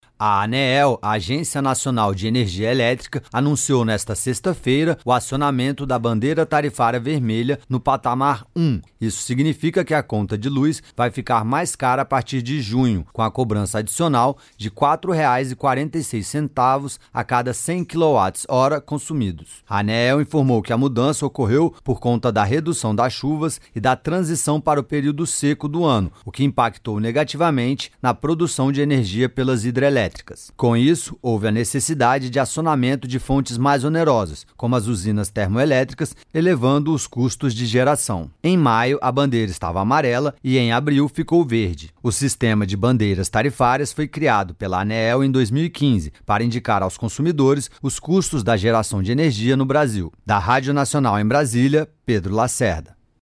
Em entrevista à Rádio Nacional